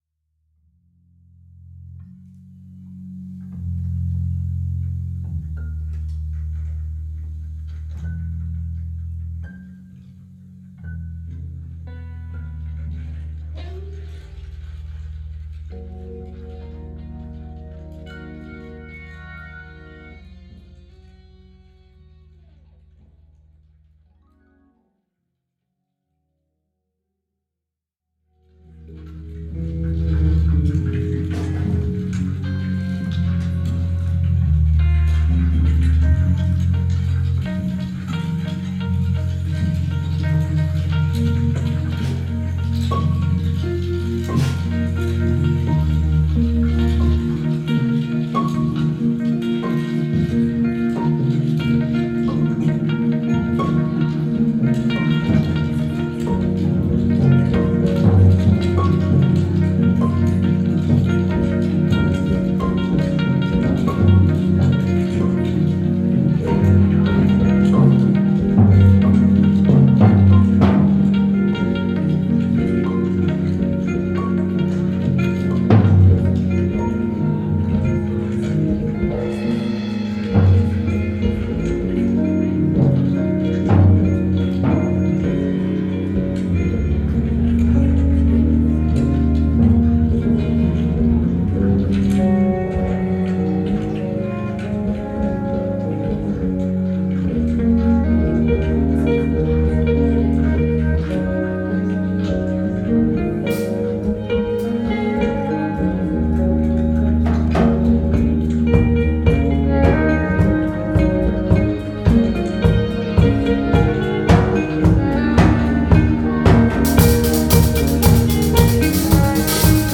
live bei der Jazznacht Soest am 07.02.2026
Keyboard/Synthesizer
Flügelhorn/E-Gitarre
E-Gitarre
Bass/Samples
Schlagzeug